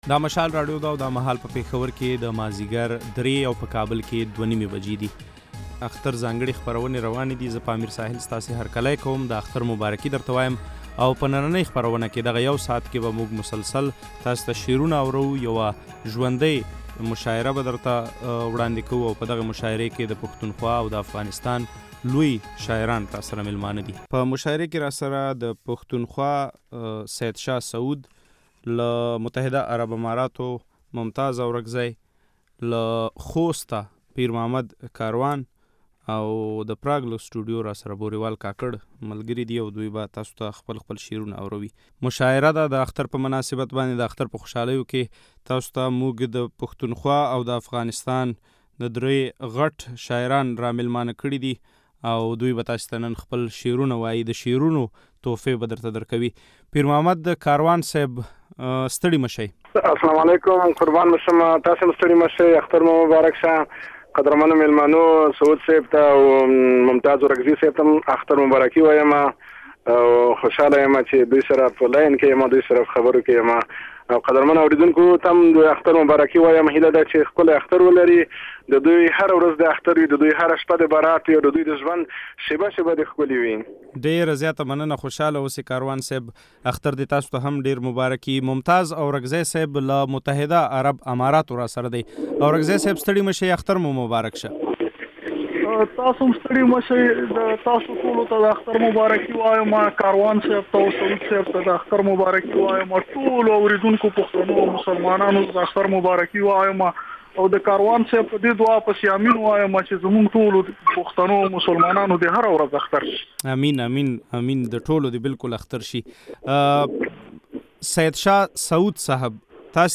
د اختر مشاعره